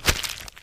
STEPS Soft Plastic, Walk 02.wav